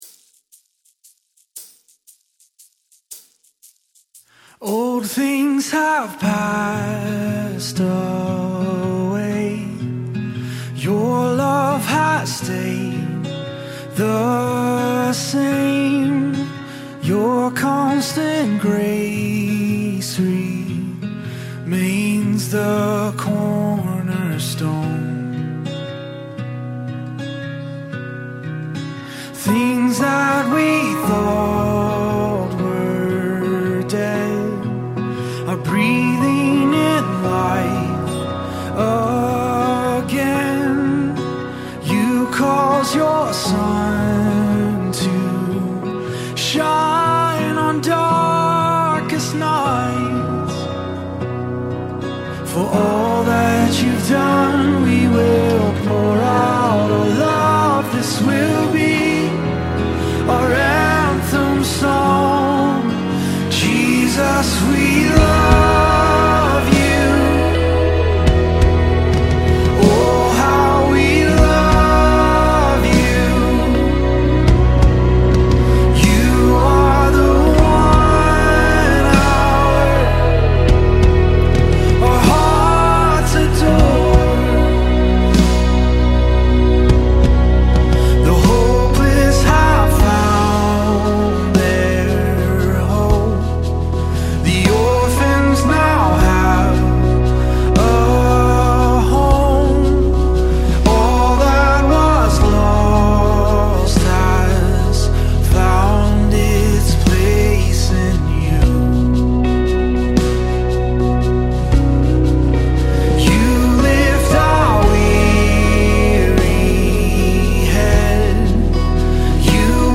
961 просмотр 52 прослушивания 1 скачиваний BPM: 142